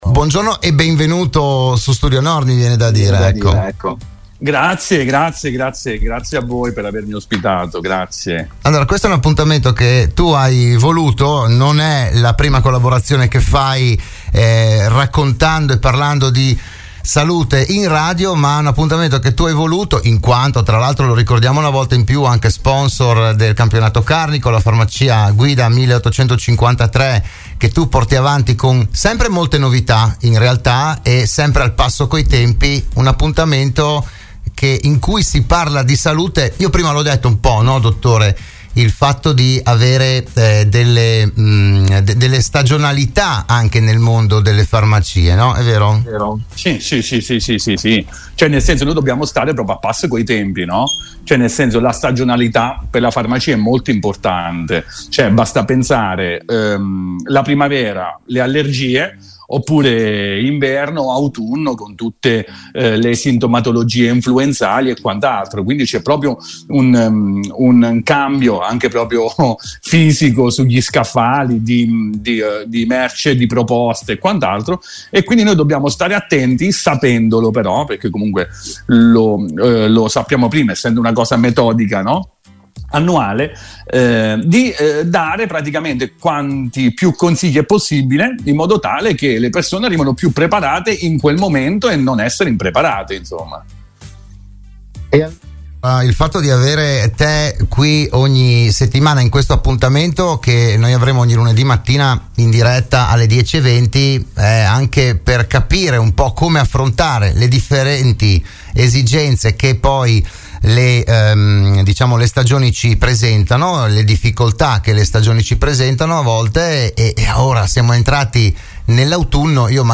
Ha preso il via oggi “Buongiorno Dottore”, il nuovo programma di prevenzione e medicina di Radio Studio Nord.